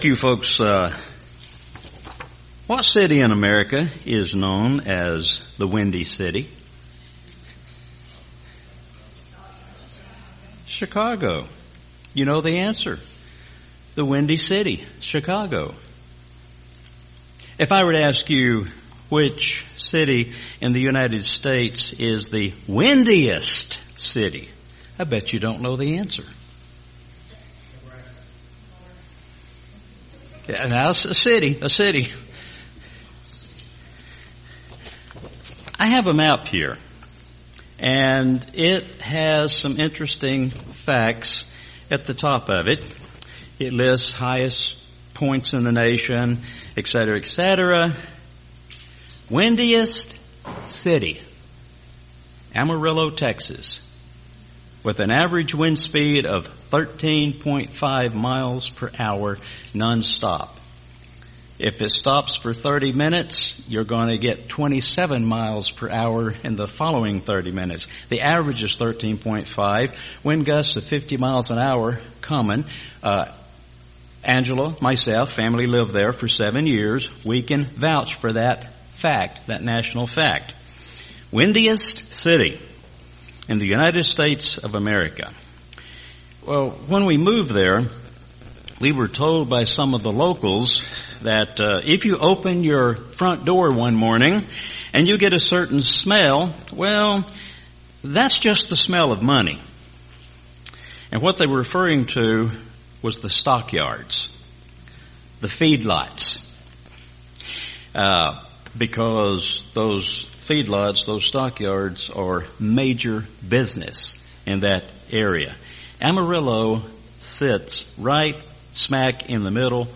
This is such an important sermon to assist us in our walk with God in the year 2022 and beyond.